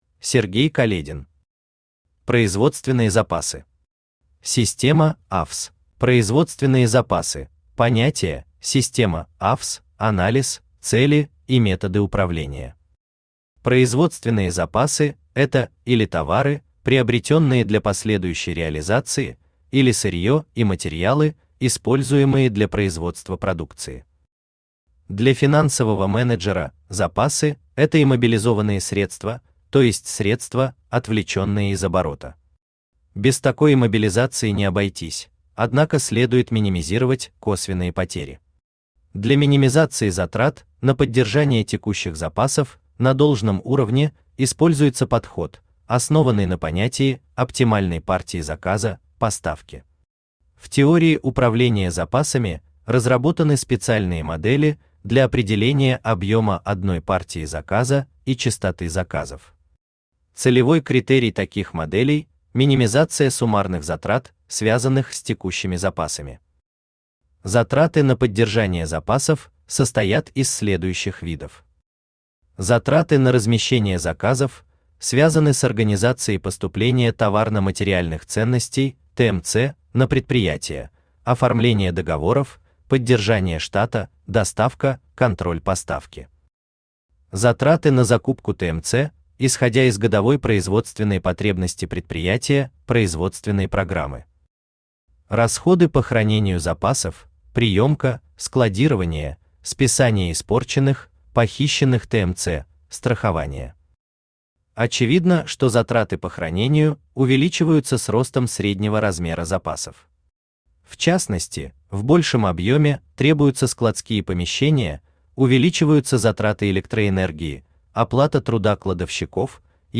Аудиокнига Производственные запасы. Система АВС | Библиотека аудиокниг
Система АВС Автор Сергей Каледин Читает аудиокнигу Авточтец ЛитРес.